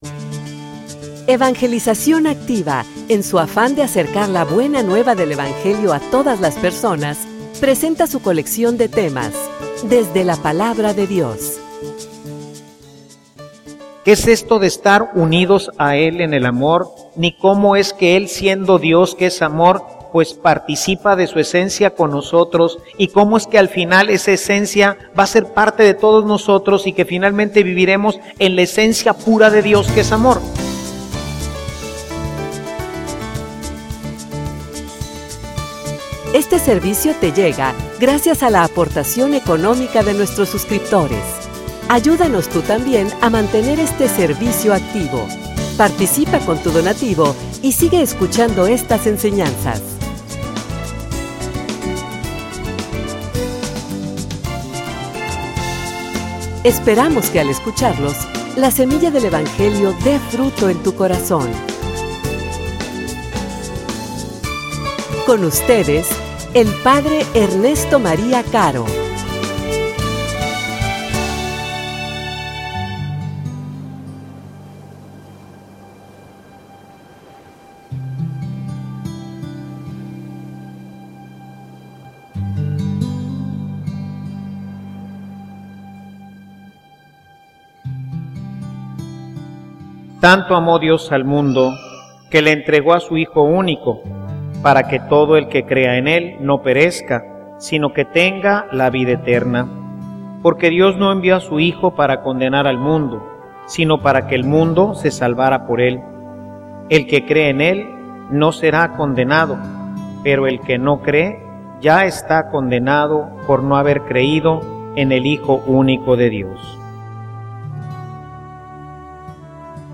homilia_Quien_me_ve_a_mi_ve_a_Dios.mp3